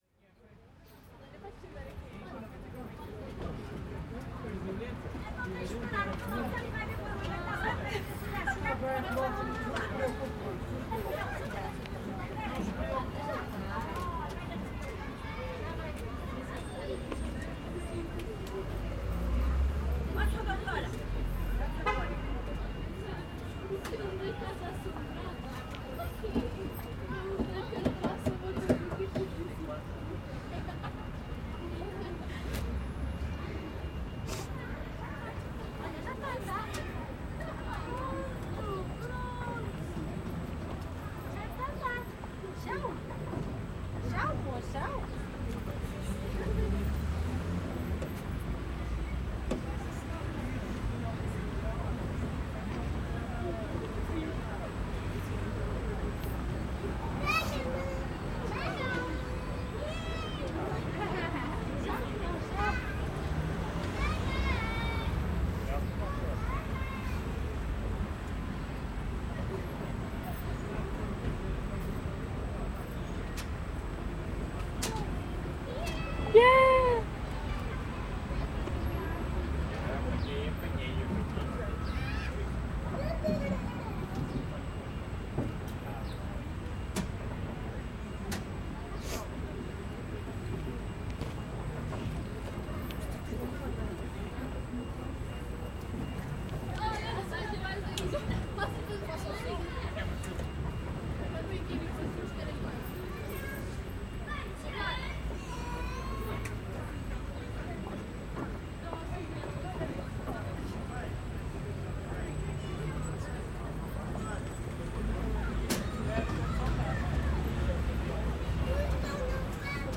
Gravação dos sons da praça a partir de dentro de um carrossel. Gravado com Edirol R44 e um par de microfones de lapela AT899.
Tipo de Prática: Paisagem Sonora Rural
Viseu-Praça-do-Rossio-Carrossel.mp3